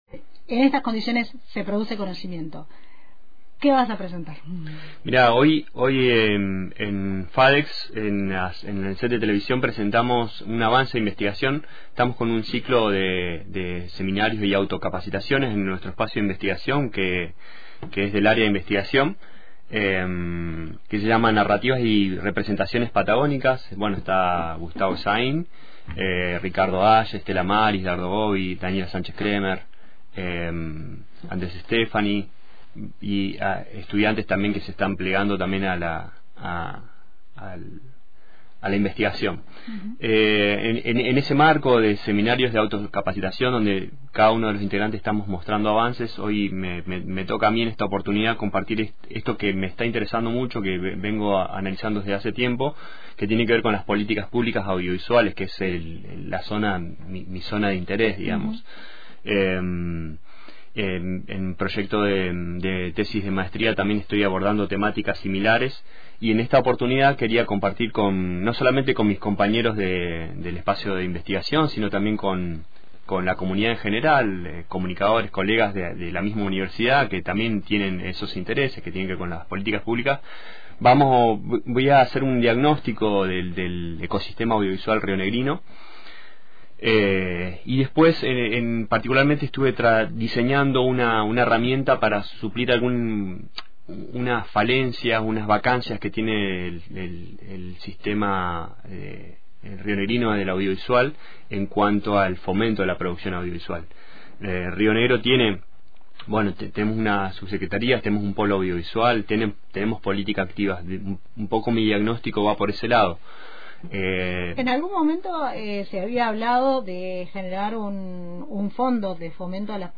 En otro tramo de la entrevista, se refirió a la situación que atraviesa el Instituto Universitario Patagónico de las Artes, no sólo desde la cuestión salarial, sino también en relación al funcionamiento orgánico de la entidad. Informó que este 5 de septiembre habrá una medida conjunta entre el gremio SITRAIUPA y ADIUPA, con un paro por 24 horas.